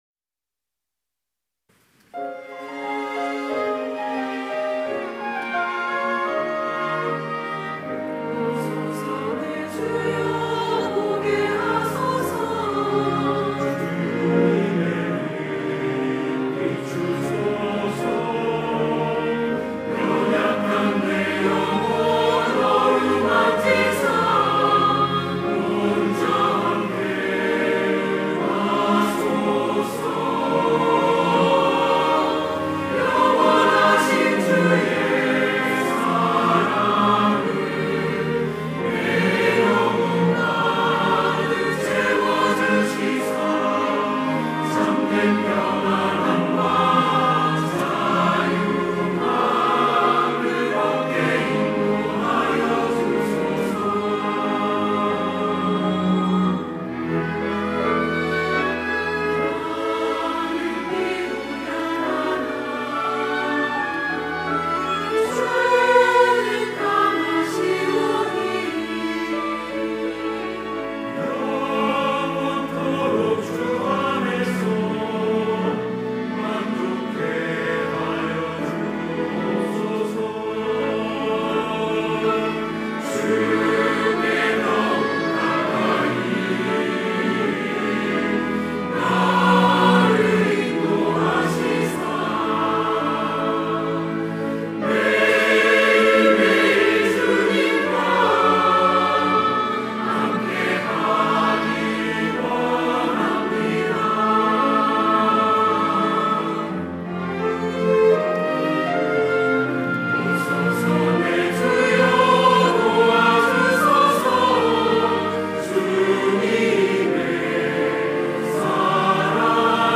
호산나(주일3부) - 오소서, 내 주여
찬양대 호산나